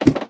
ladder3.ogg